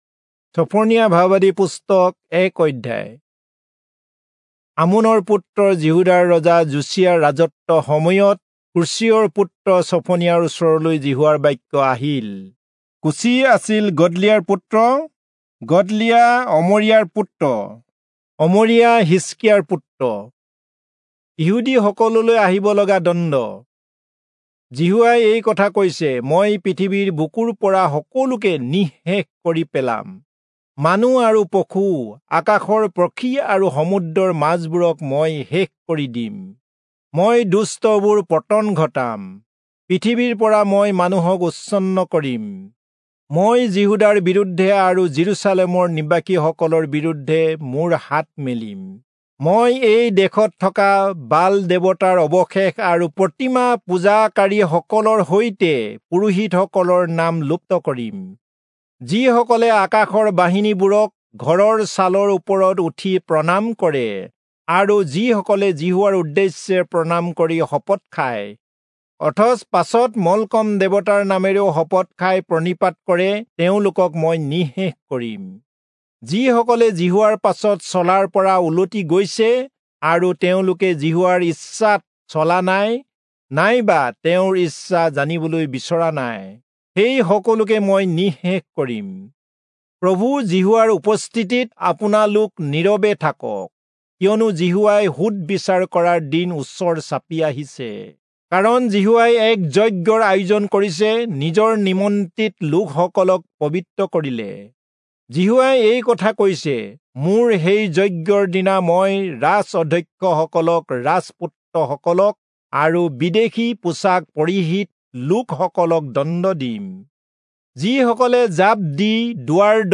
Assamese Audio Bible - Zephaniah 1 in Irvkn bible version